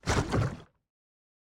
Minecraft Version Minecraft Version 1.21.5 Latest Release | Latest Snapshot 1.21.5 / assets / minecraft / sounds / mob / strider / step_lava1.ogg Compare With Compare With Latest Release | Latest Snapshot
step_lava1.ogg